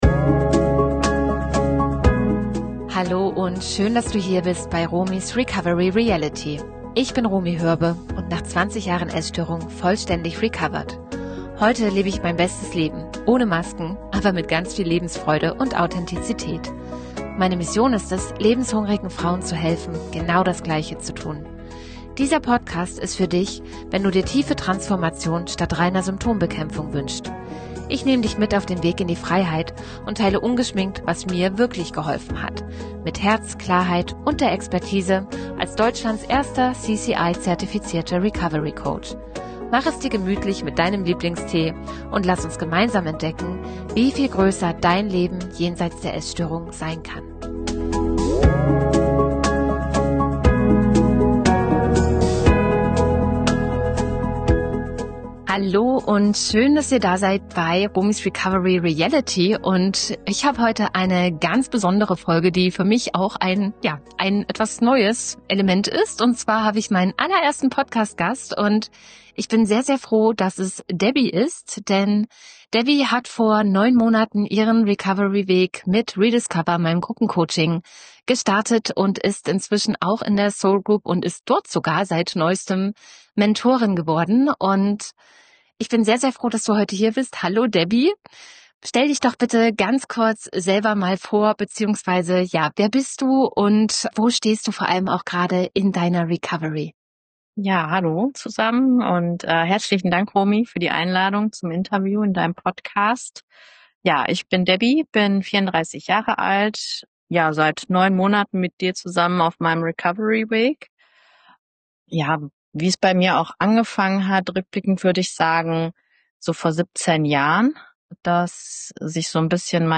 In dieser besonderen Episode begrüße ich meinen ersten Podcast-Gast